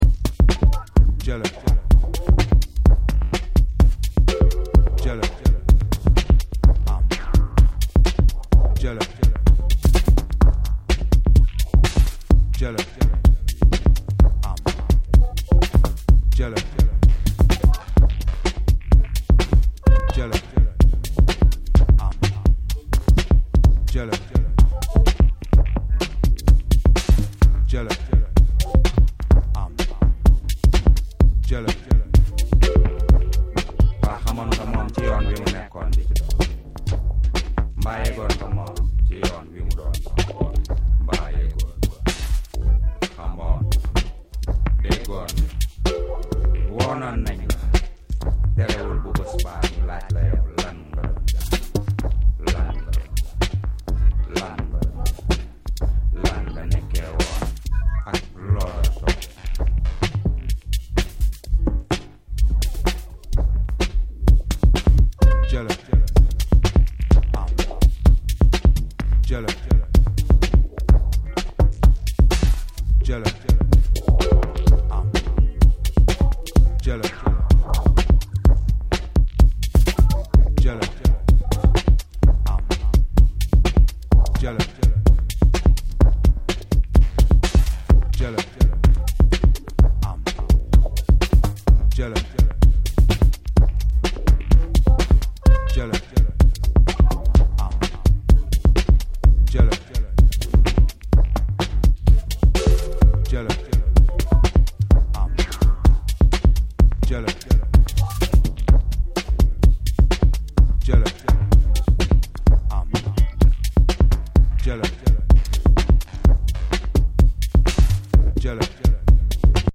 captivating tones of Senegalese vocalist